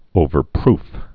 (ōvər-prf)